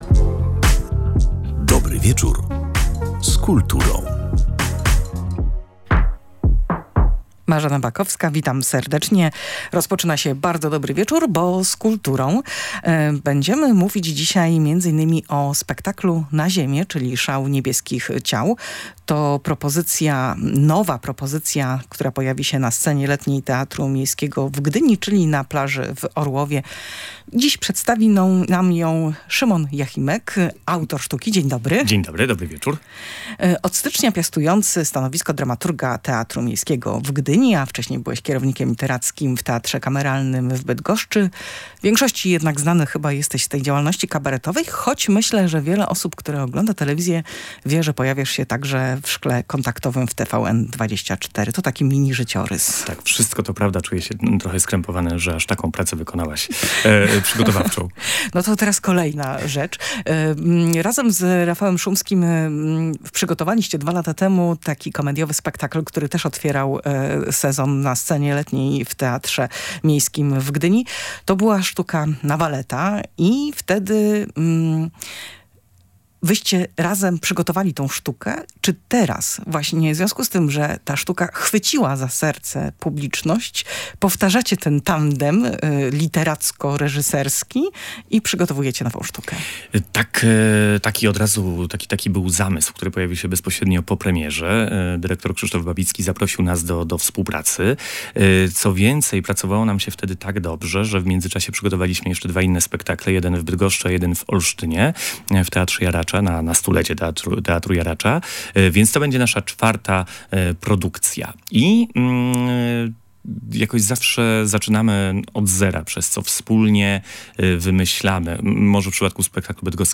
W audycji „Dobry wieczór z kulturą” opowiadał o nim